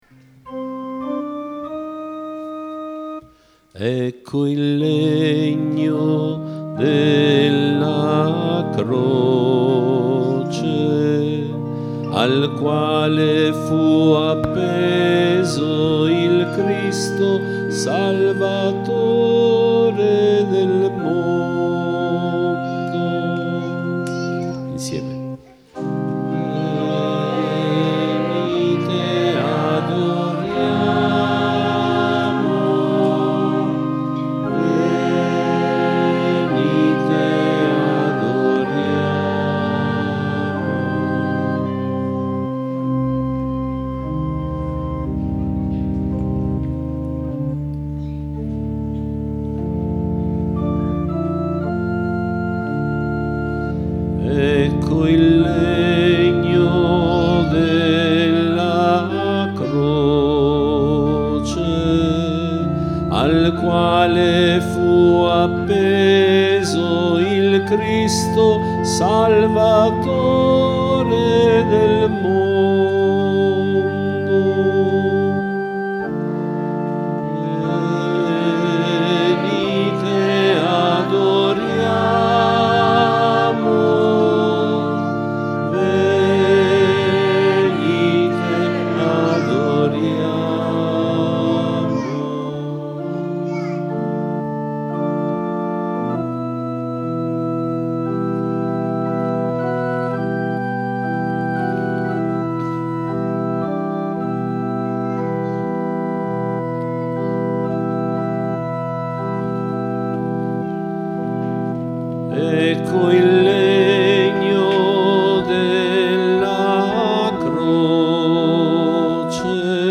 Canti Venerdì Santo 2022